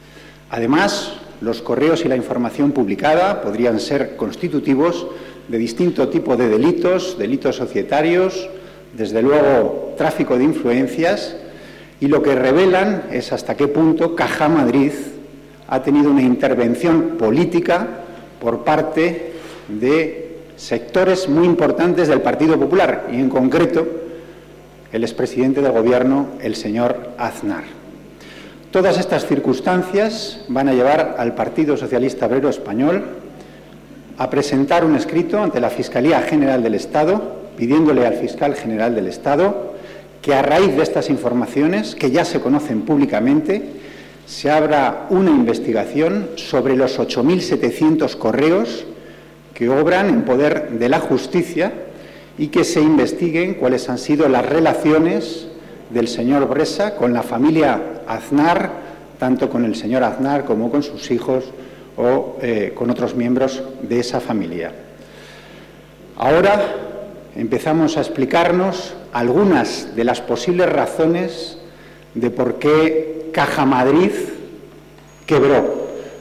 Declaraciones de Antonio Hernando en el Congreso sobre los correos entre Blesa y la familia Aznar 18/12/2013